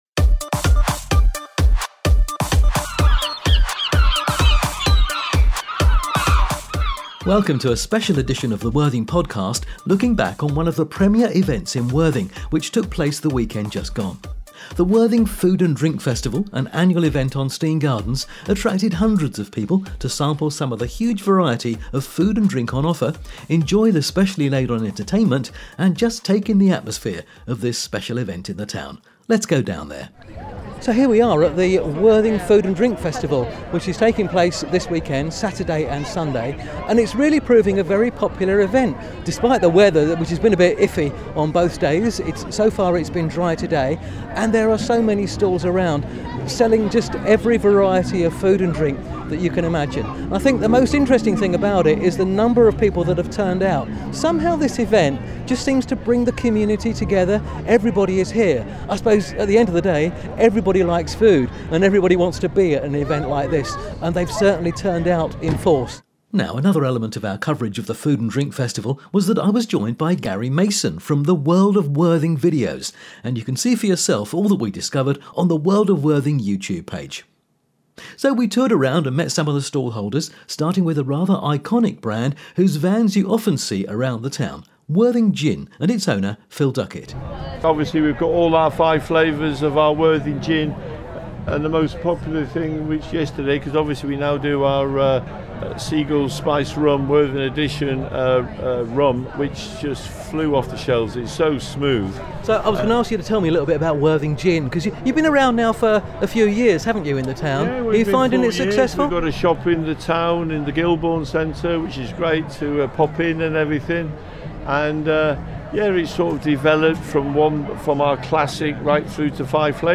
visits this premier festival in Worthing and talks to stall holders